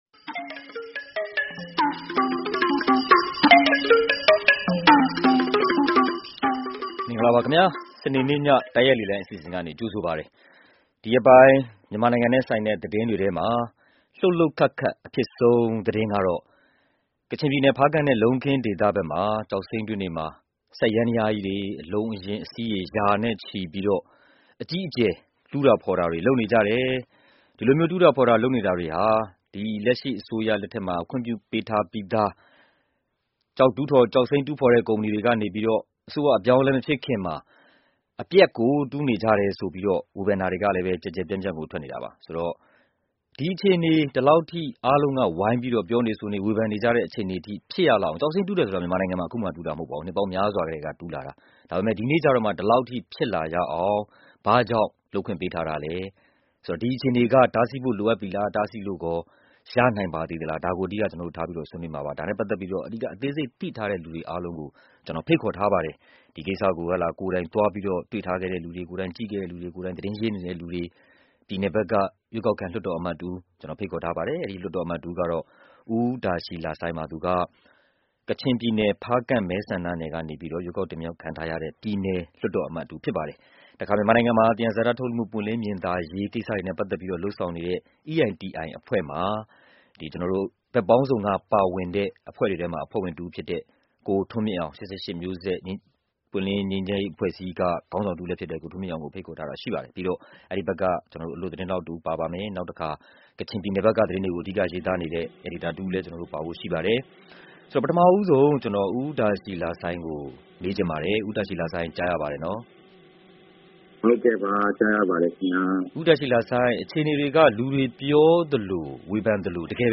ဖားကန့်မှာ ကျောက်စိမ်းတွေကို အပြင်းအထန် တူးဖော်နေတဲ့အပေါ် သဘောထားအမြင်တွေကို စနေည တိုက်ရိုက်လေလှိုင်းအစီအစဉ်မှာ ဆွေးနွေးထားပါတယ်။
အပြောင်ရှင်းနေတဲ့ ဖားကန့်ကျောက်စိမ်းတွင်း (စနေ တိုက်ရိုက်လေလှိုင်းဆွေးနွေးခန်း)